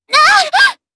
Shamilla-Vox_Damage_jp_03.wav